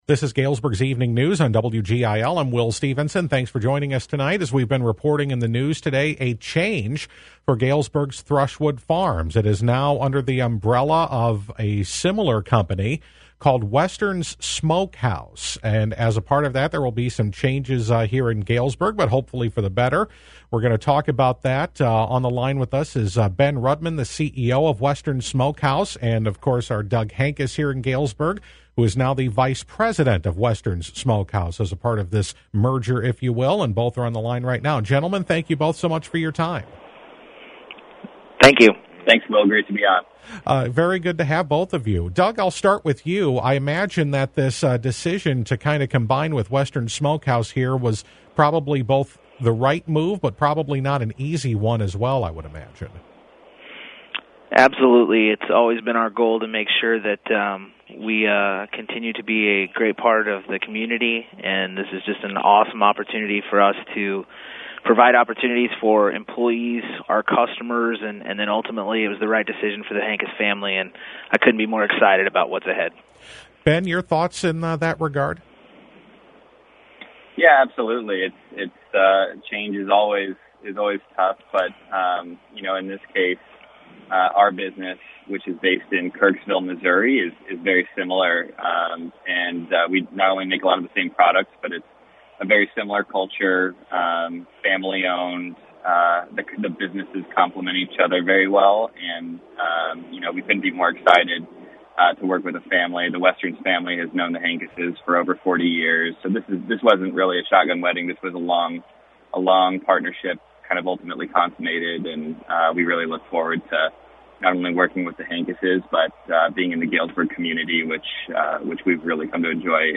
Here is our full interview with two of the people responsible for Galesburg's Thruswood Farms Quality Meats merging with Missouri-based Western's Smokehouse.